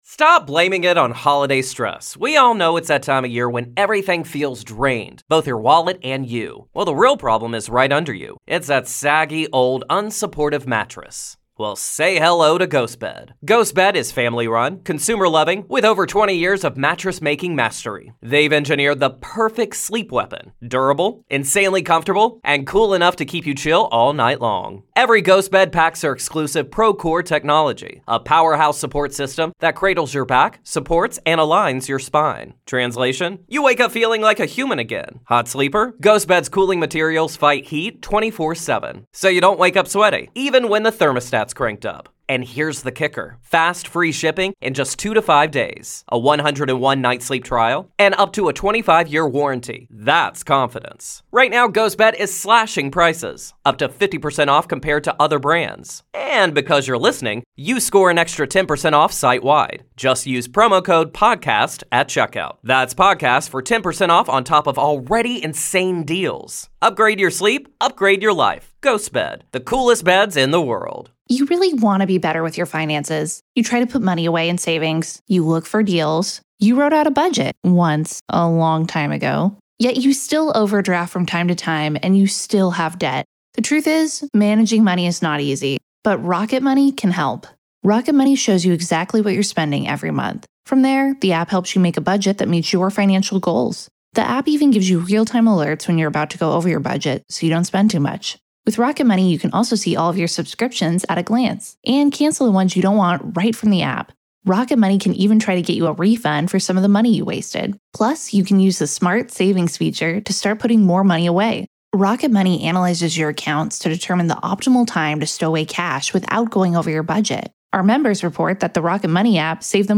Full Interview